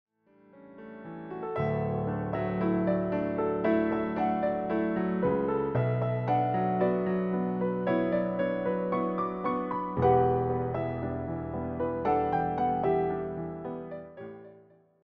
The performance favors clean voicing and balanced dynamics